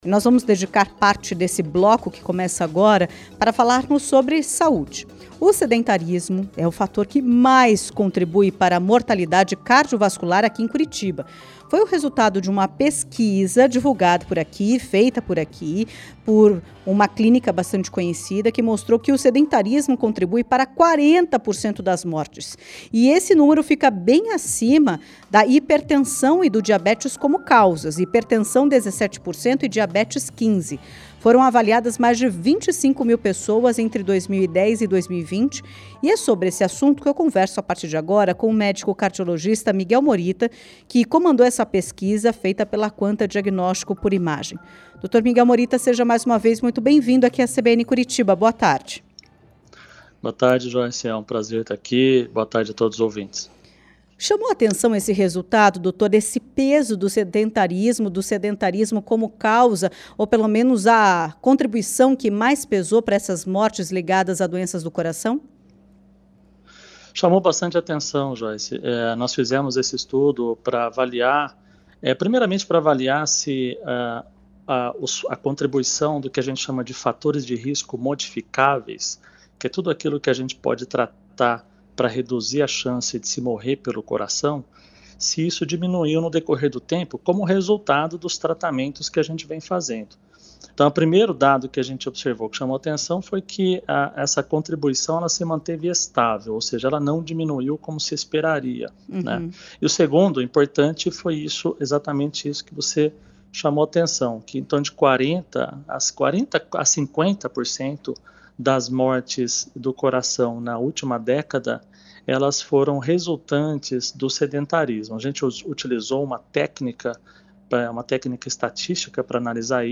entrevista-sedentarismo.mp3